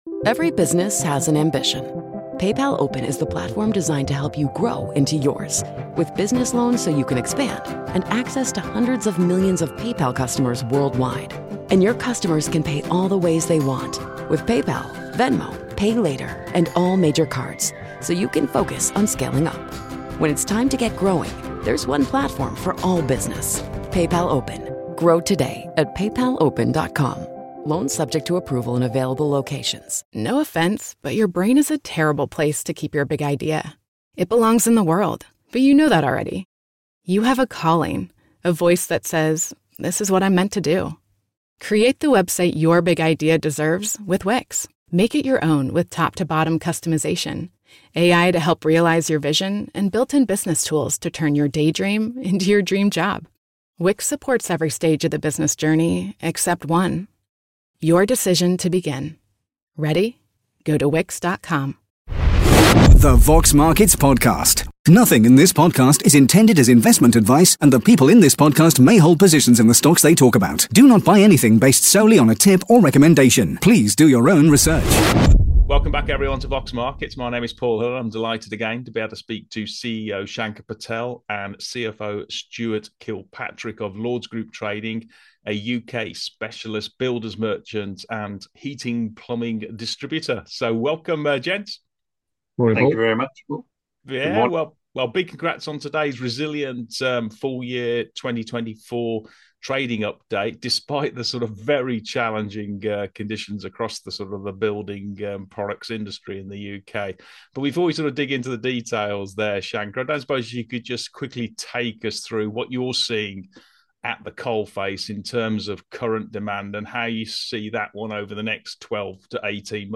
The Vox Markets Podcast / Q&A